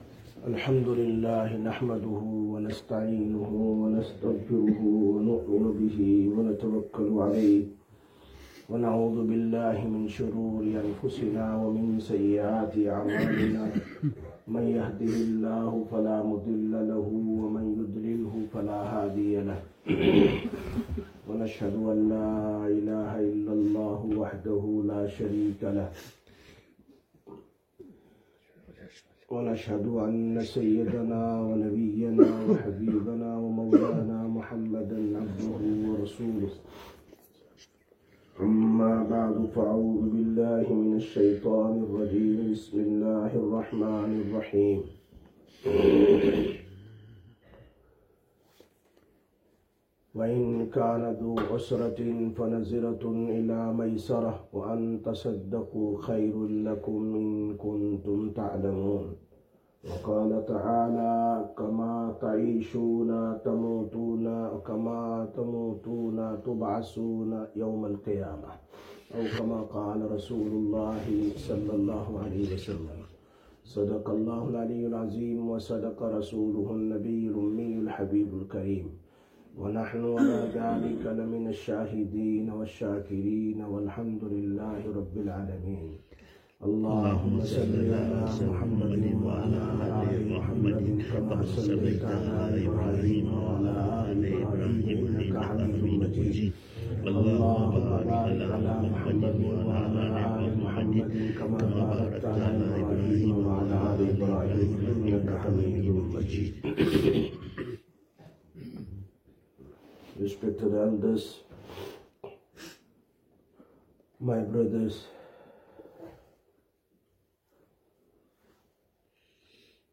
10/04/2026 Jumma Bayan, Masjid Quba